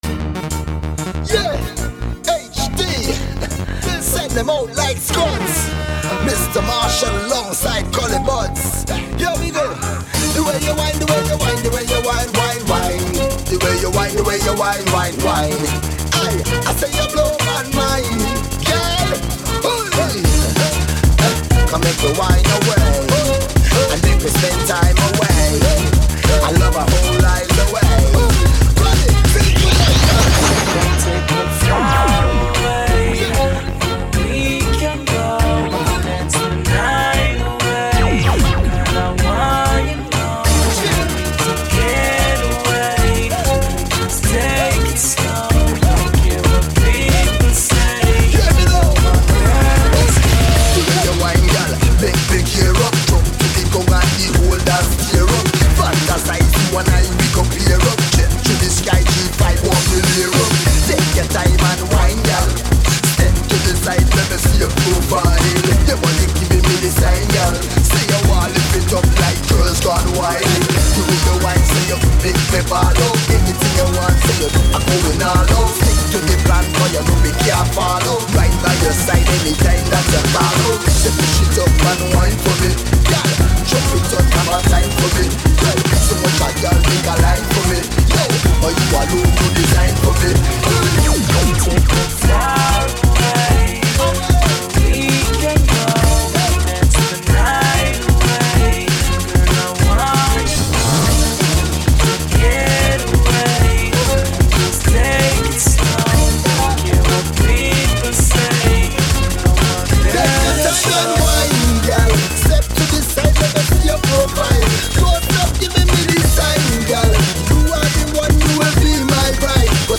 Drum'n'bass Descàrregues i reproduccions